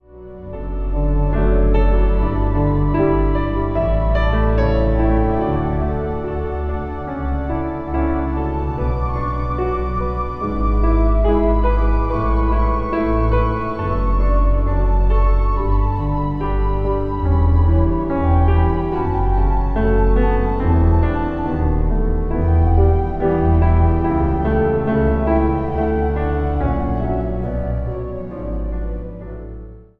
Instrumentaal
Instrumentaal | Piano
(Piano solo)